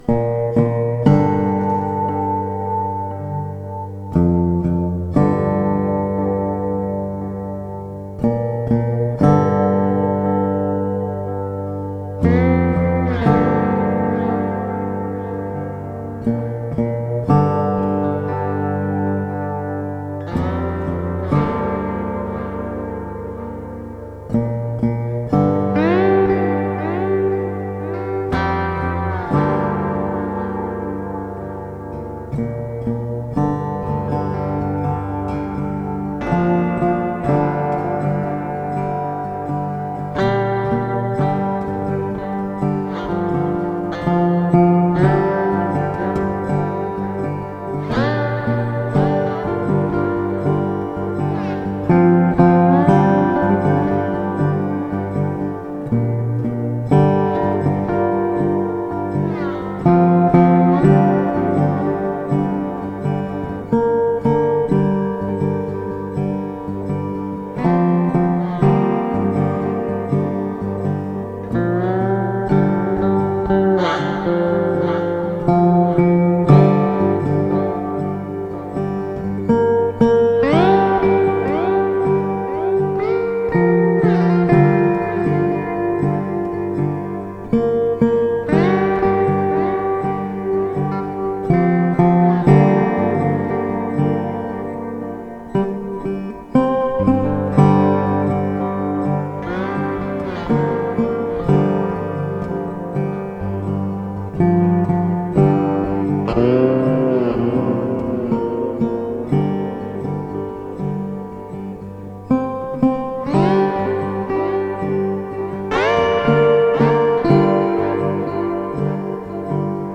Slow Folk Blues Dobro and Steel Guitar.